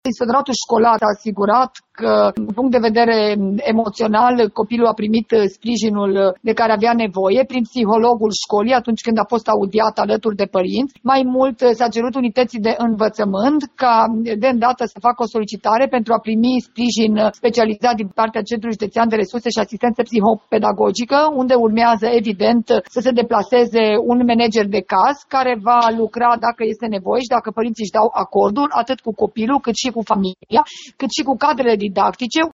Între timp, fetiţa de 7 ani, care l-a surprins pe bărbat la toaletă, este consiliată psihologic – a declarat inspectorul șef al ISJ Timiș, Aura Danielescu.